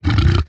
1.21.4 / assets / minecraft / sounds / mob / hoglin / idle3.ogg